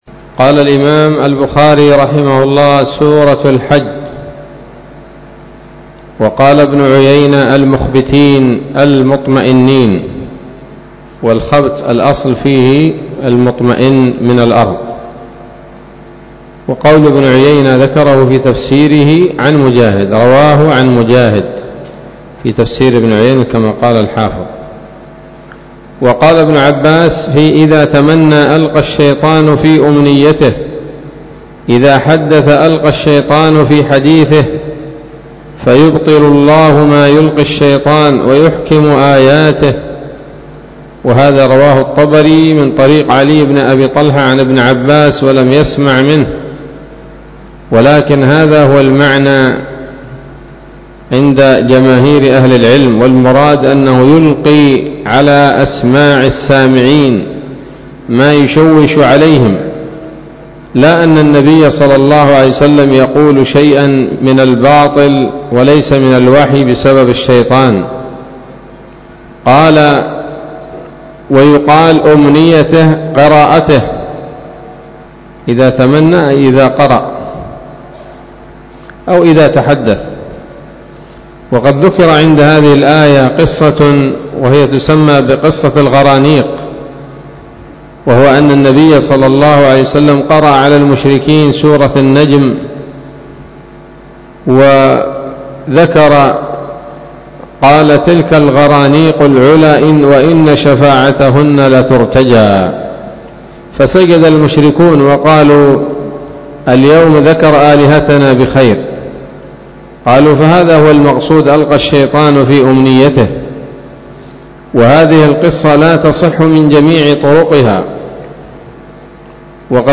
الدرس الخامس والسبعون بعد المائة من كتاب التفسير من صحيح الإمام البخاري